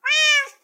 sounds / mob / cat / meow4.ogg
meow4.ogg